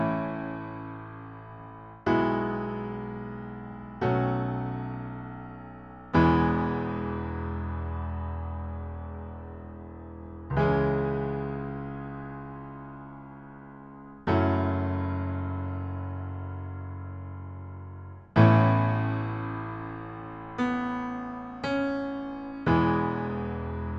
Single Version Pop (2010s) 3:43 Buy £1.50